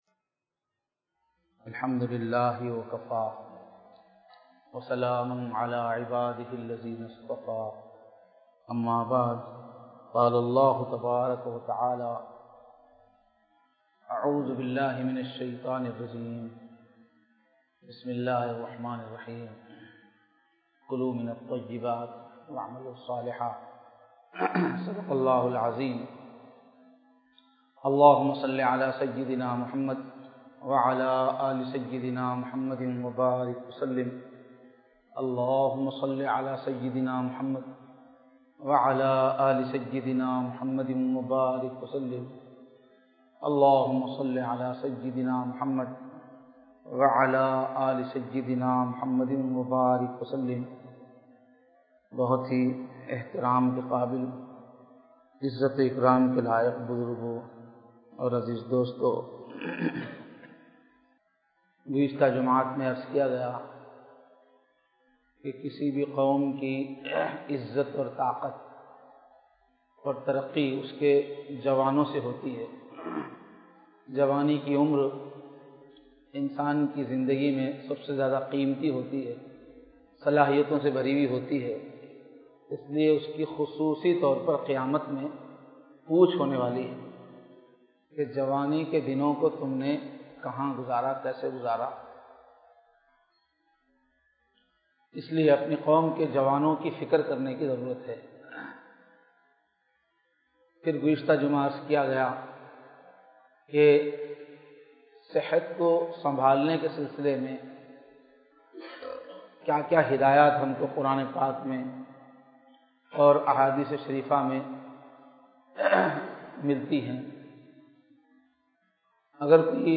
Bayanath
Jummah